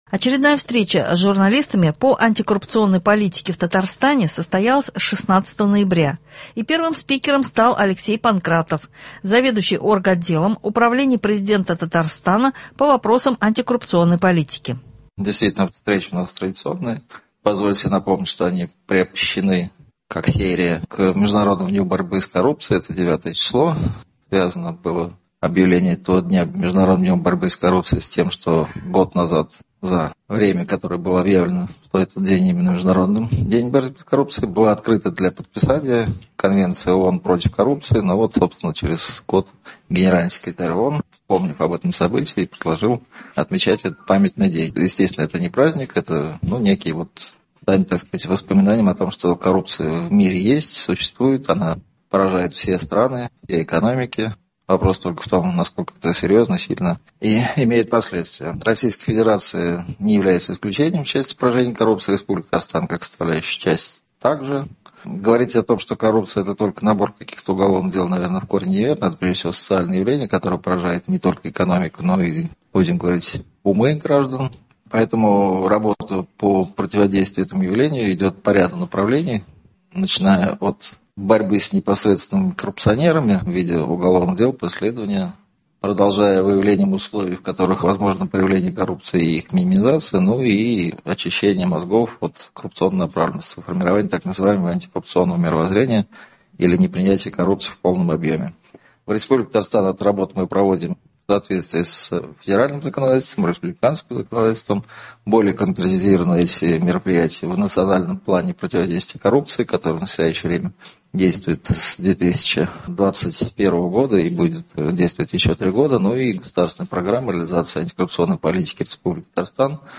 О параметрах федерального бюджета, о мерах поддержки семей мобилизованных и других актуальных вопросах шла речь в студии ГТРК Татарстан, гостем эфира стал депутат Госдумы от Татарстана Айрат Фаррахов.